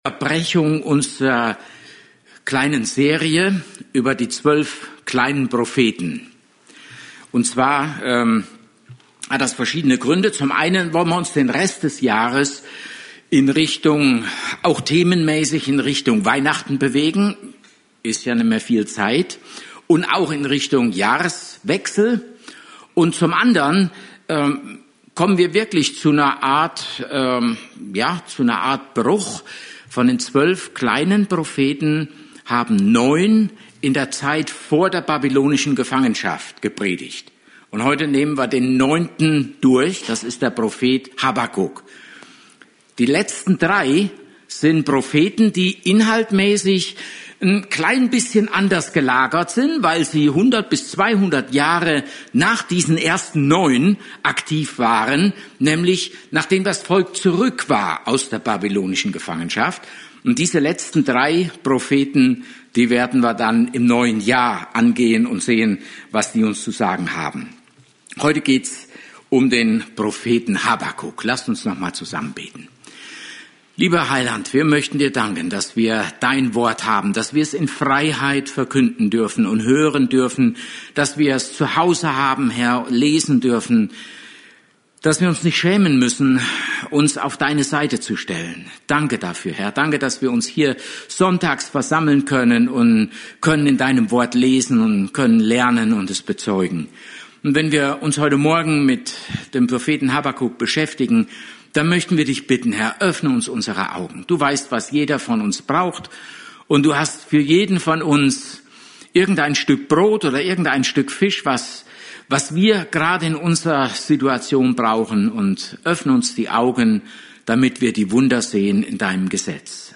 Service Type: Gottesdienst